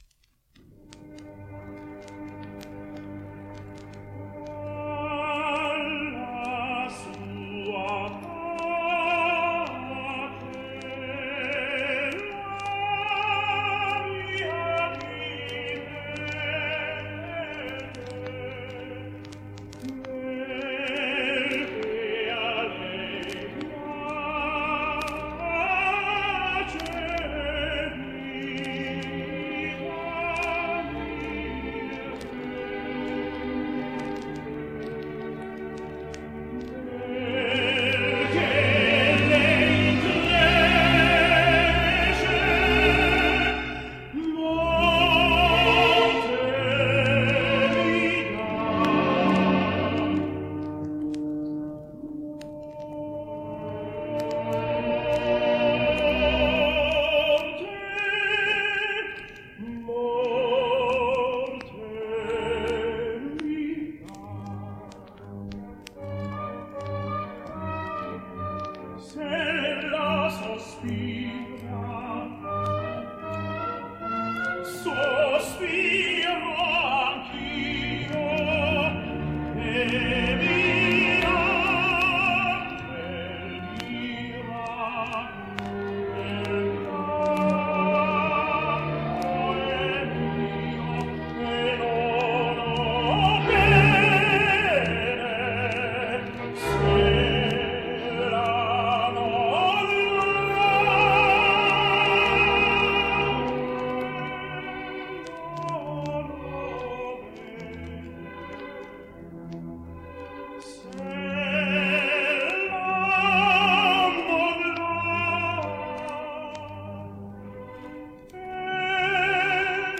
German Tenor
By the mid-60s, he was acclaimed as a lively elegant and accomplished Mozart tenor.
His record is the Aria, Dallas Sua Pace from Mozart’s Don Giovanni.